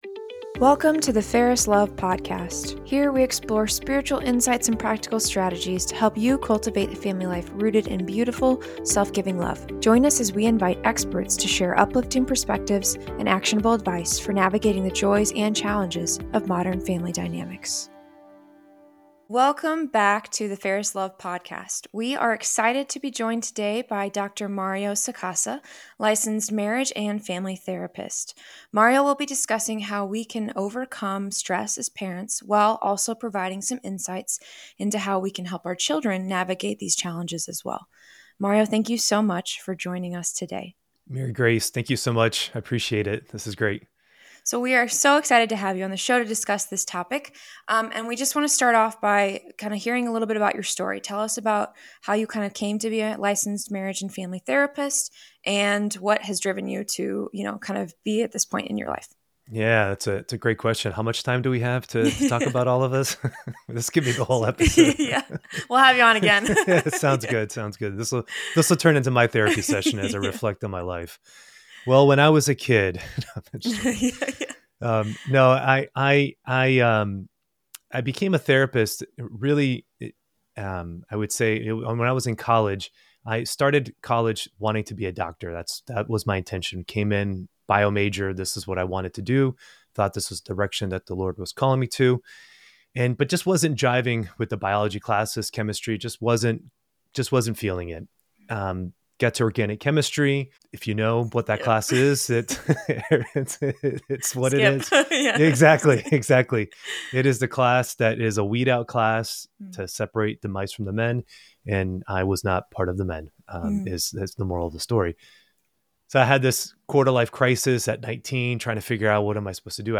This interview is an inspiration to grow in a deeper relationship with Mary!